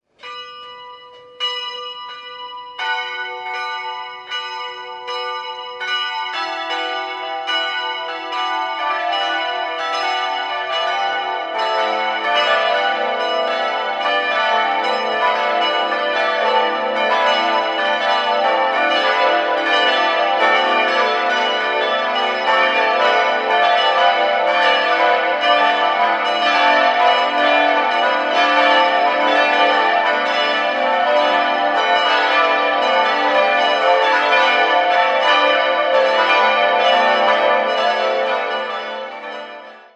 6-stimmiges Geläute: a'-h'-d''-e''-fis''-h''
Gnadenglocke e'' 250 kg 1958 Friedrich Wilhelm Schilling, Heidelberg
Ein sehr originelles, glockenreiches Kleingeläute in schwerer Rippe mit interessanter Tonfolge.